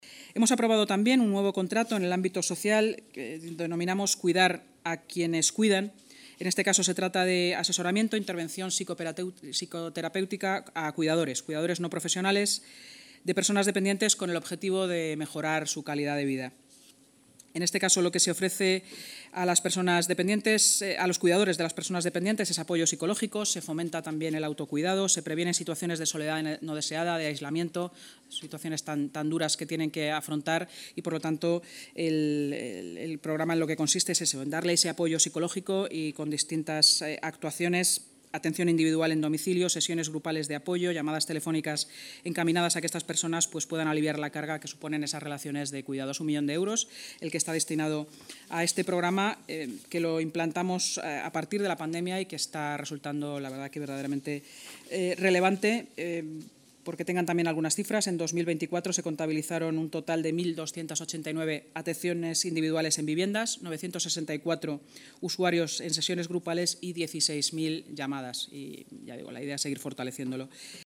Nueva ventana:Vicealcaldesa y portavoz municipal, Inma Sanz: